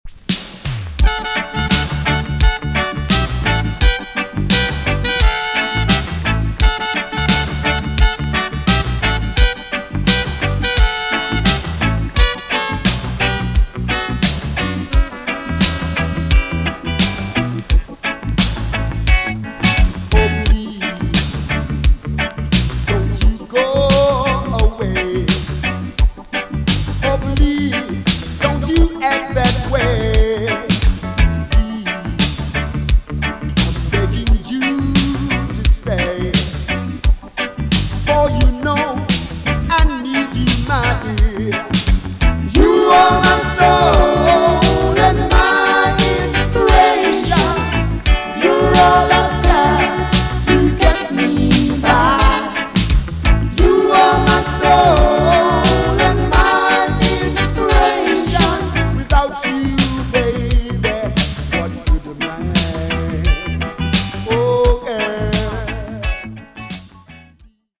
TOP >DISCO45 >80'S 90'S DANCEHALL
EX- 音はキレイです。
B.SIDE NICE SOUL COVER TUNE!!